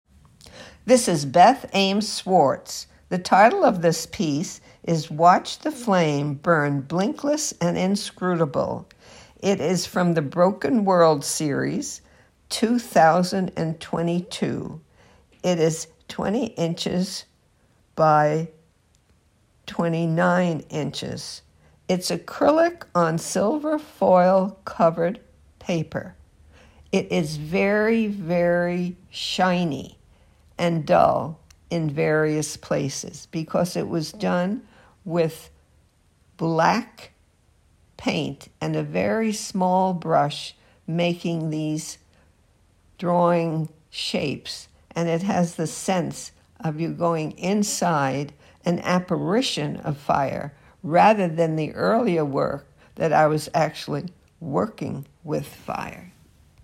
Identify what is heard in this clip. Audio Description: